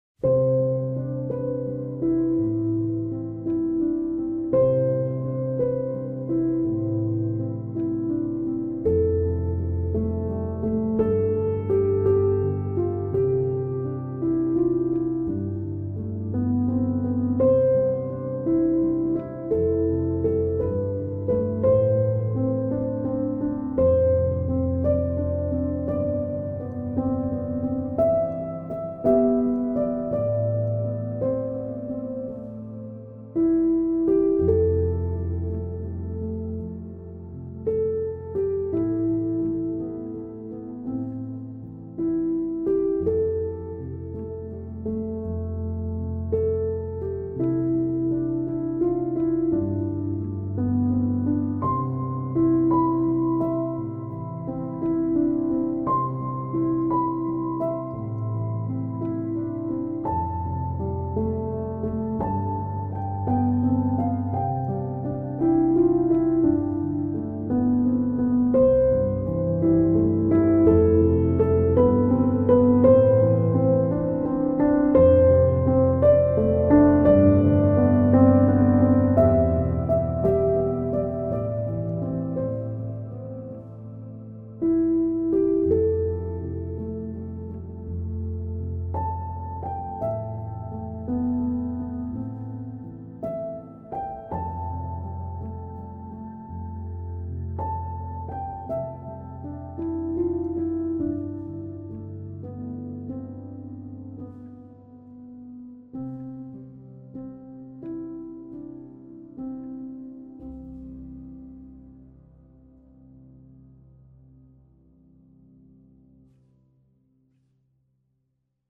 پیانو
موسیقی بی کلام آرامش بخش الهام‌بخش پیانو